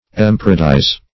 Emparadise \Em*par"a*dise\